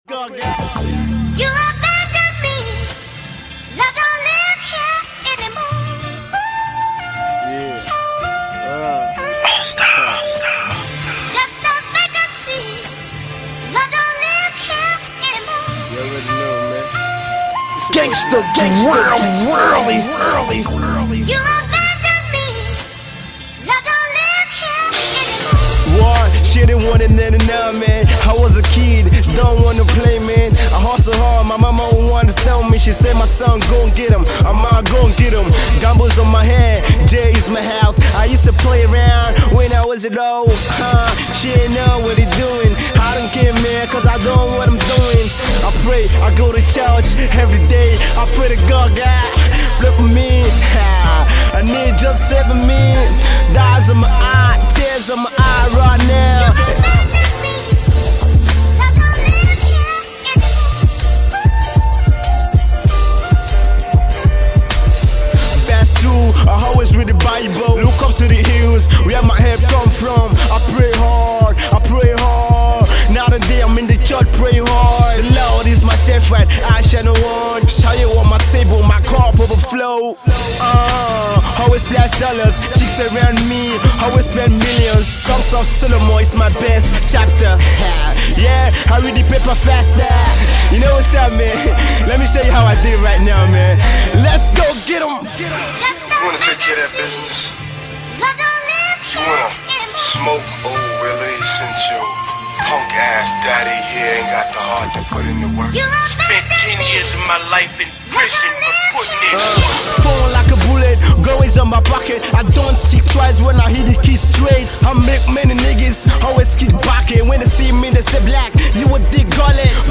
Rap
Nigerian Hip-Hop